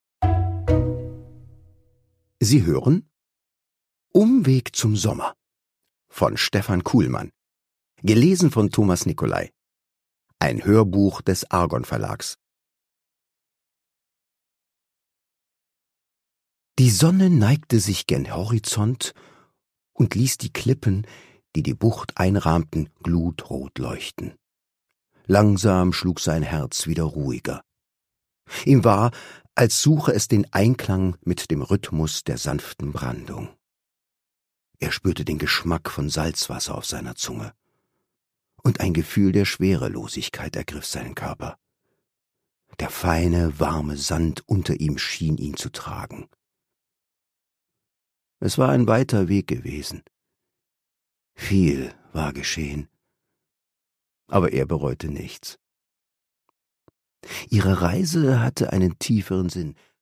Produkttyp: Hörbuch-Download
Gelesen von: Thomas Nicolai